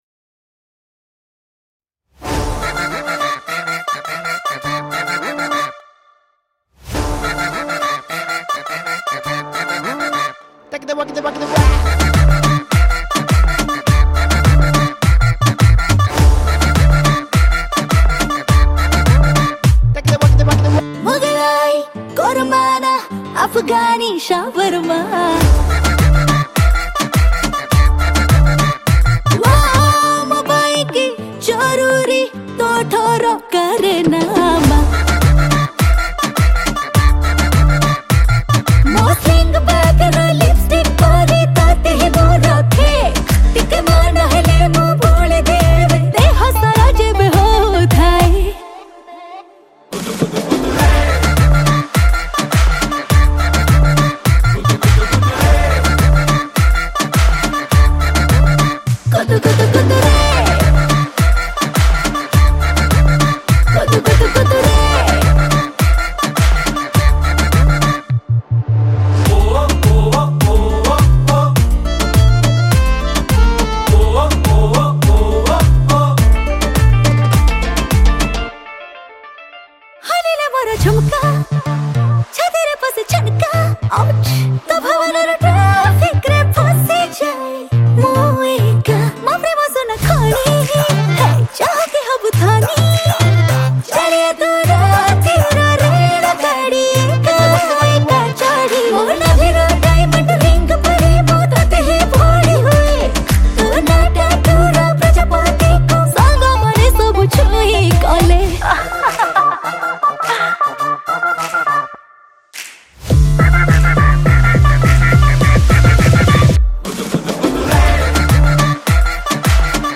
Keyboard